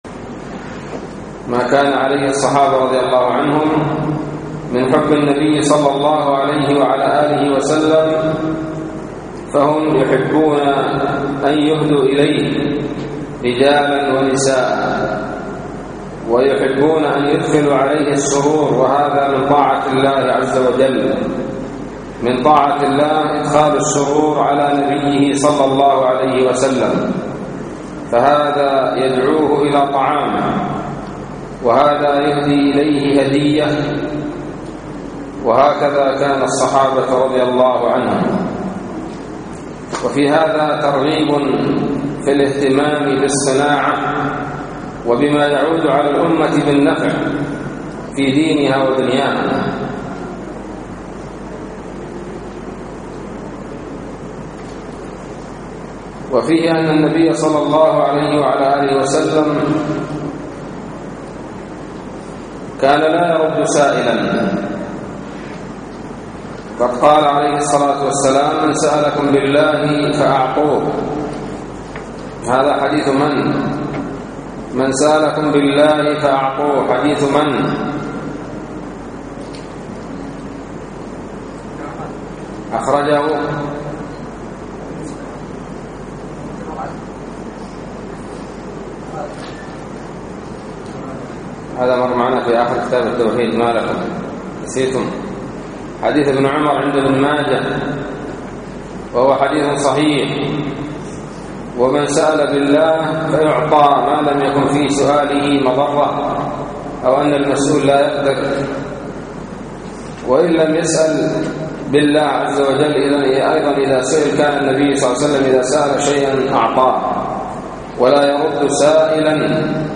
الدرس السابع والعشرون : باب ذكر النساج